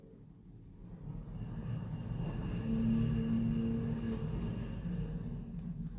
Lift moving 1.mp3